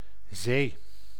English: Dutch pronunciation of "zee"